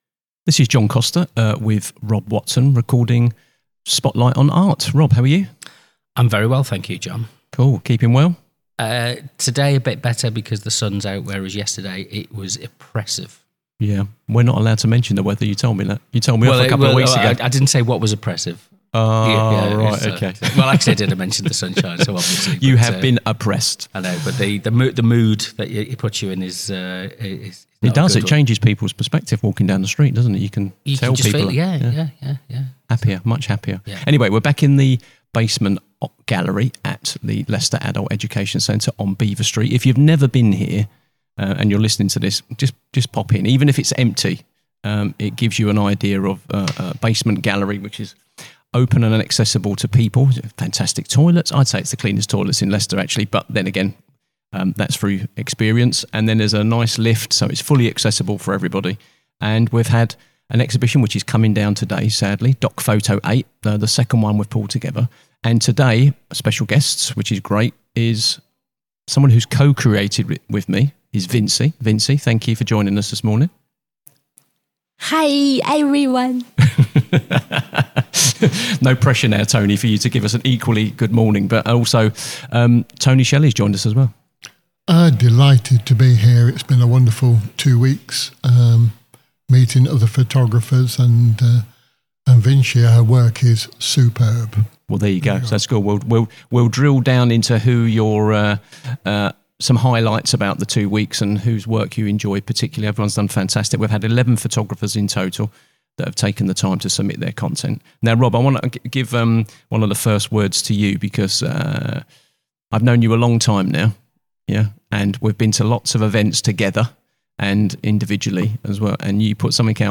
Hear from photographers and curators about creativity, collaboration, and the evolving role of photography in documenting life, culture, and history.
The latest episode of Spotlight on Arts takes us to the basement gallery of Leicester’s Adult Education Centre, where the DocPhoto 8 exhibition recently concluded.